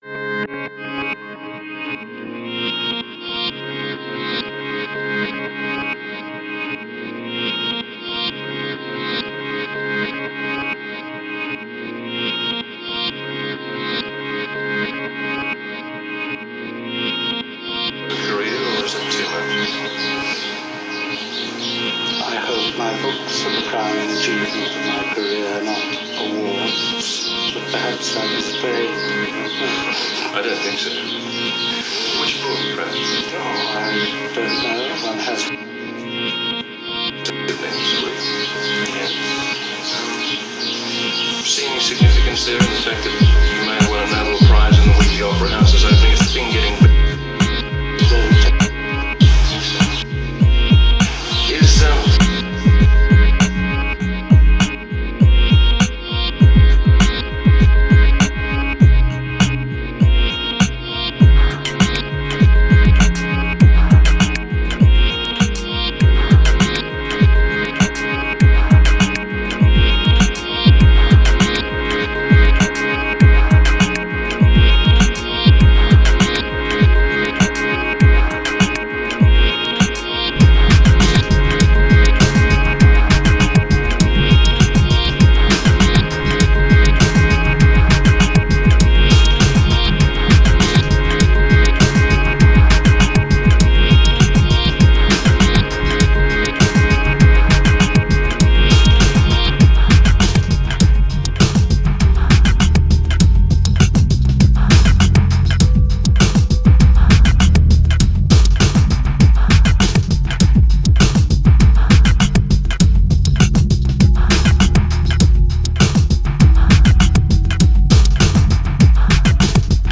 gorgeous and intricately crafted beats